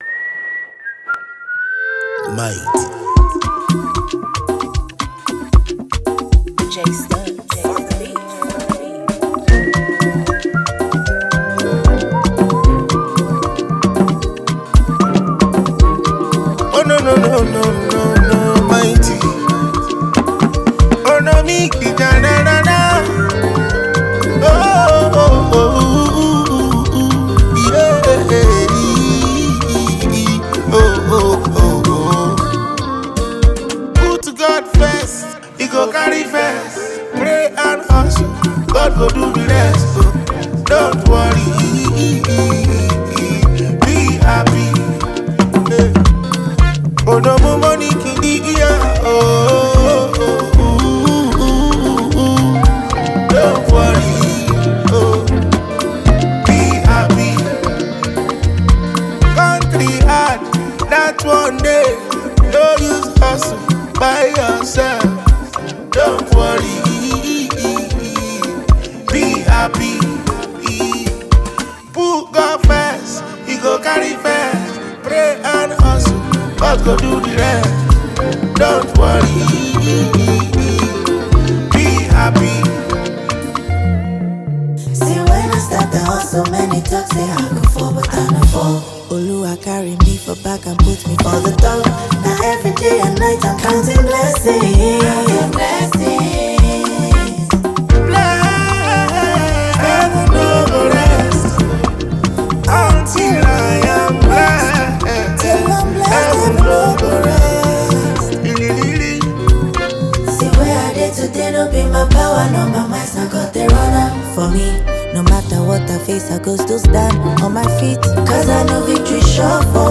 With its radio-friendly groove and inspiring lyrics